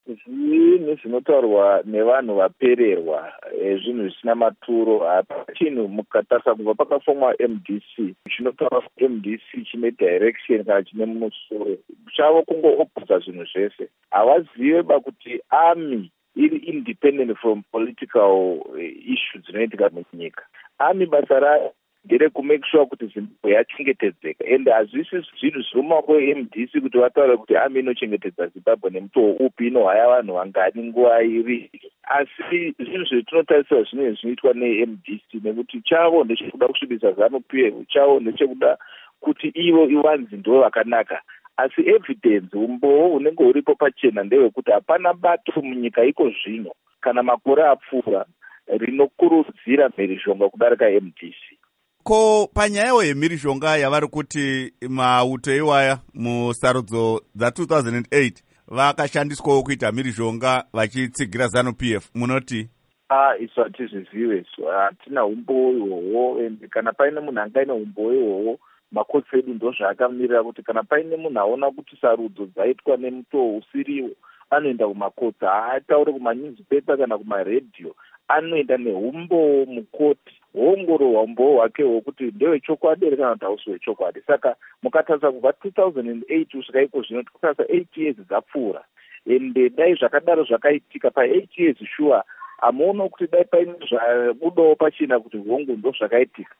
Hurukuro naVaPsychology Maziwisa